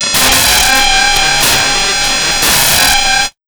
AMB147NOIS-R.wav